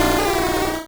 Cri d'Otaria dans Pokémon Rouge et Bleu.